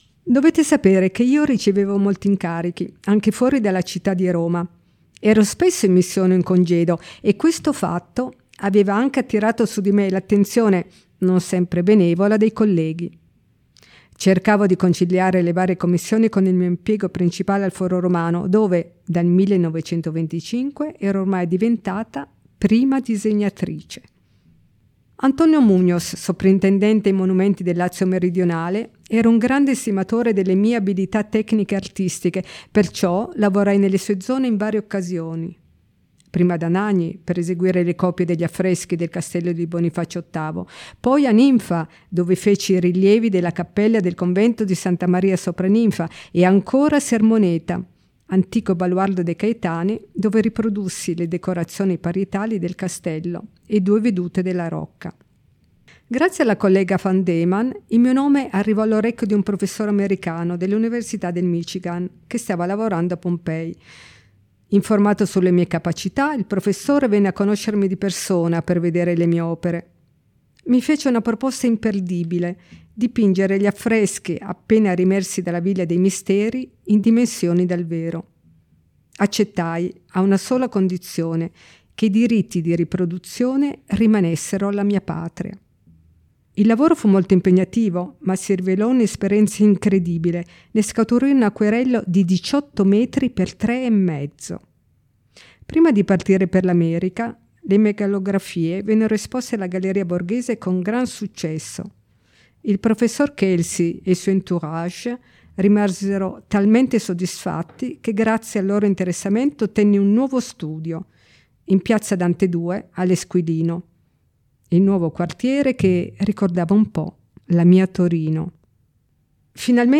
• approfondimenti audio, con il racconto in prima persona di Maria Barosso e le audiodescrizioni delle opere selezionate
Storytelling Maria Barosso: